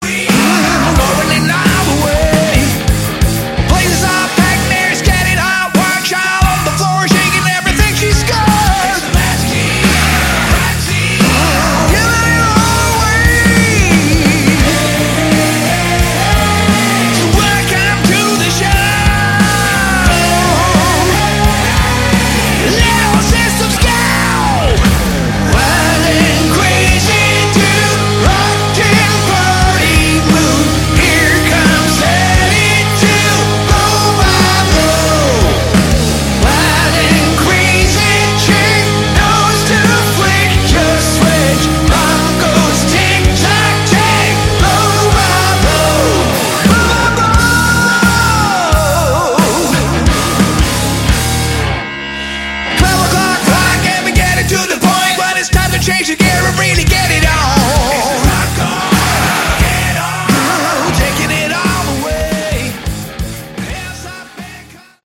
Category: Hard Rock
guitar, vocals, keyboards
bass
drums